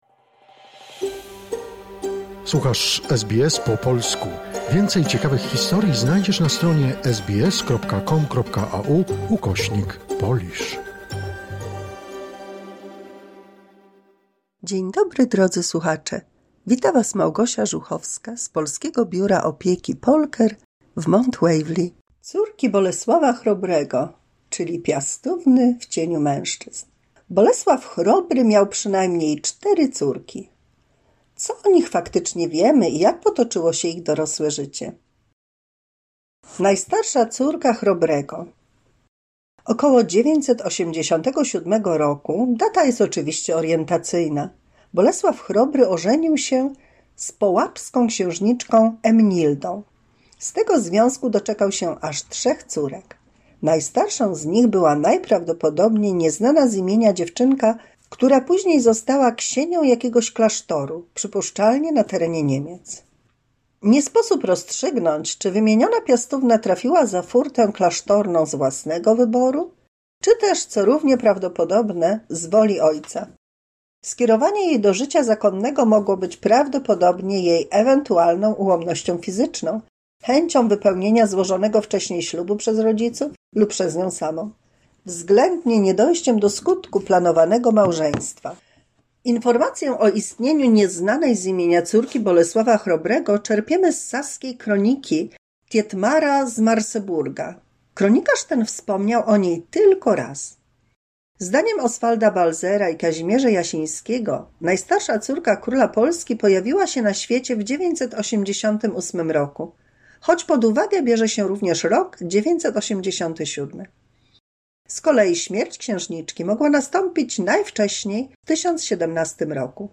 W 215 mini słuchowisku dla polskich seniorów usłyszymy o córkach Bolesława Chrobrego.